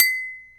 Index of /90_sSampleCDs/Roland L-CD701/PRC_Trash+Kitch/PRC_Kitch Tuned
PRC BOTTLE0I.wav